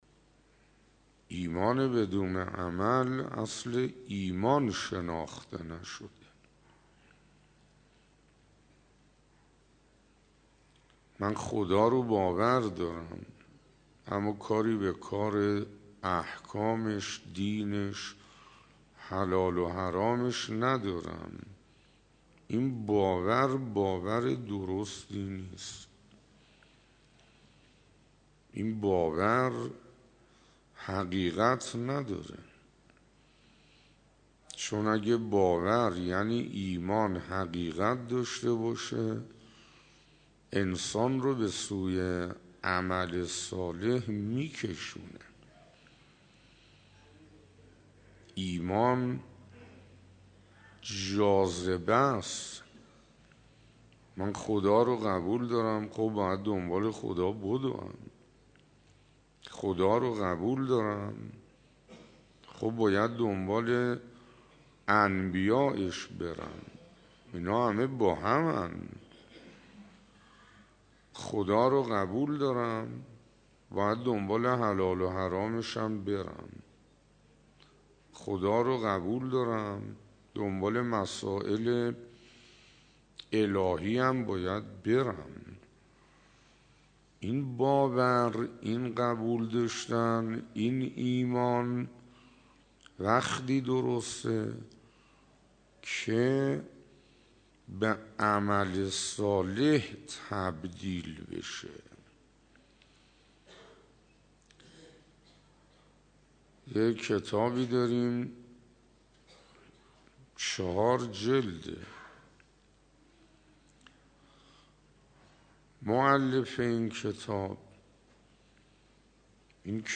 به گزارش خبرنگار خبرگزاری رسا، حجت الاسلام والمسلمین حسین انصاریان استاد حوزه علمیه، شب گذشته در مسجد حضرت رسول(ص) به ایراد سخنرانی پرداخت و گفت: طبق صریح آیات و روایات دنبال علم رفتن یک ارزش خاص است.